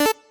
piece_rotate.ogg